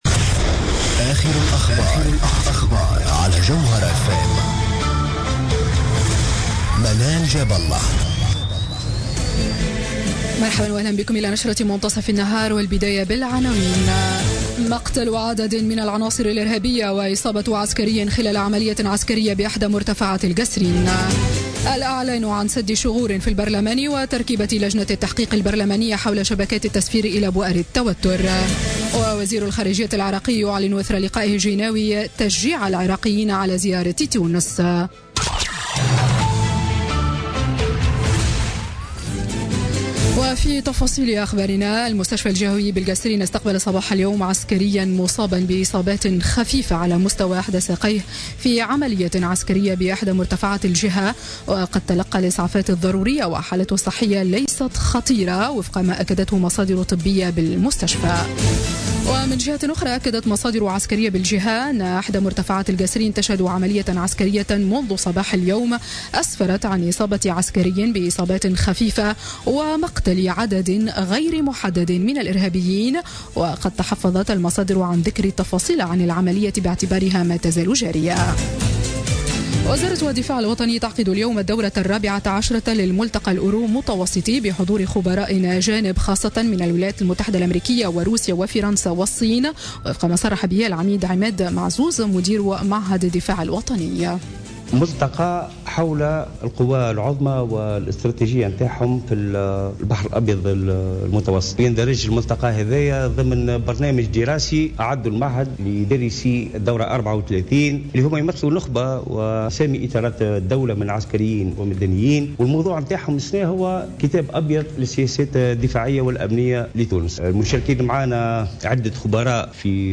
نشرة أخبار منتصف النهار ليوم الثلاثاء 28 فيفري 2017